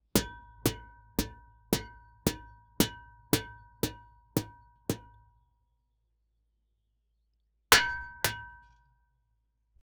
Various impacts like a human hitting rocks, hitting a tin roof, hitting a plate glass window
various-impacts-like-a-hu-u3a4hwou.wav